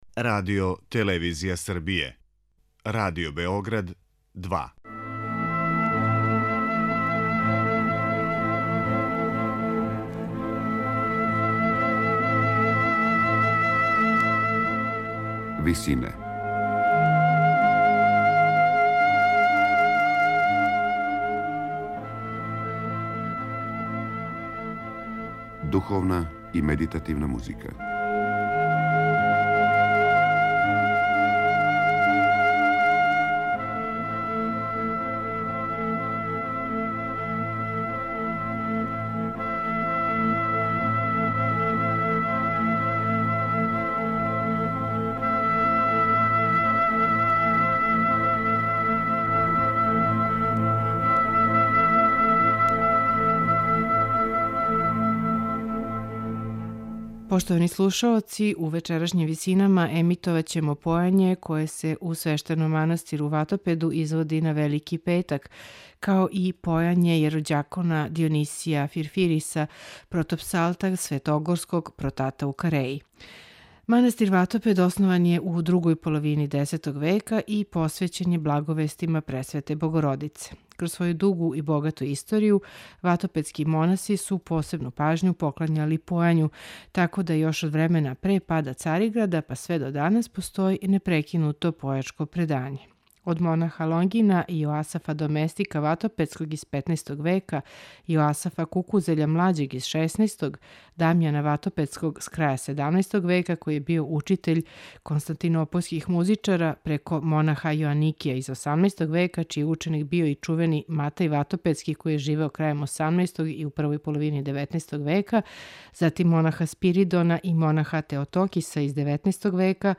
У вечерашњим 'Висинама' емитоваћемо појање које се у свештеном манастиру Ватопеду изводи на Велики петак
у ВИСИНАМА представљамо медитативне и духовне композиције аутора свих конфесија и епоха.